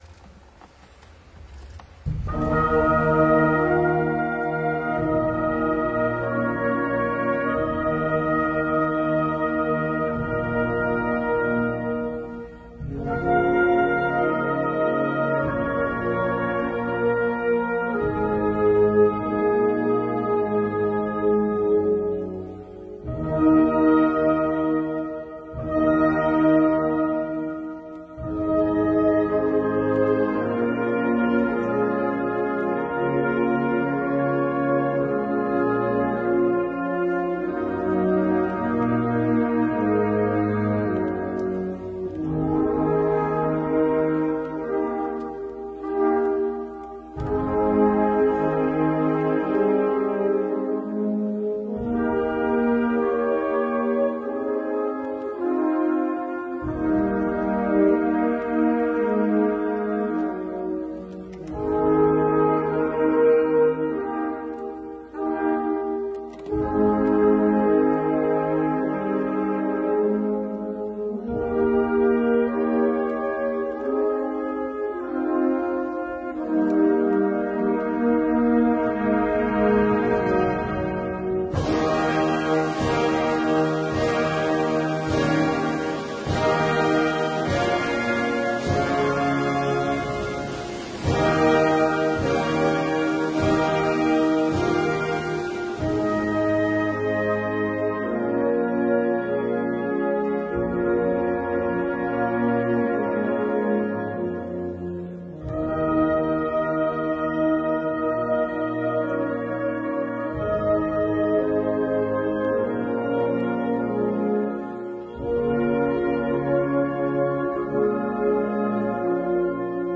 12月6日上午10时，党中央、全国人大常委会、国务院、全国政协、中央军委在北京人民大会堂隆重举行江泽民同志追悼大会。